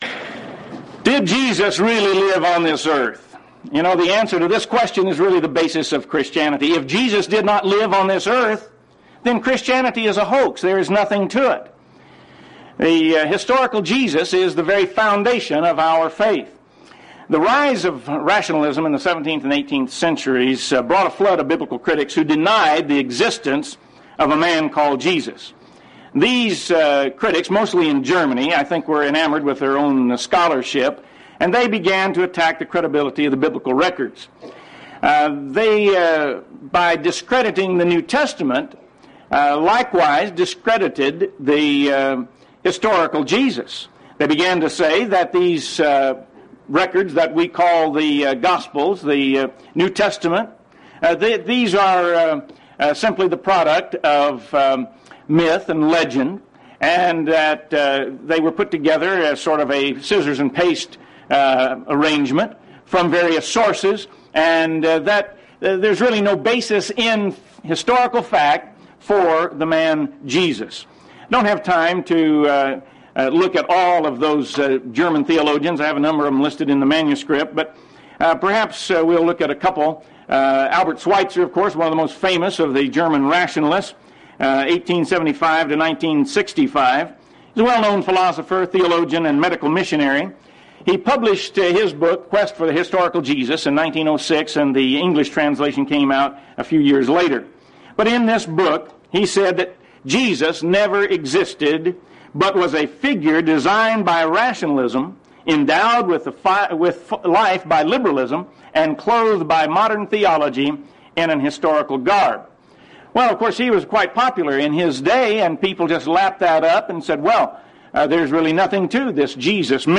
Title: Open Forum Speaker(s): Various
Event: 2nd Annual Lubbock Lectures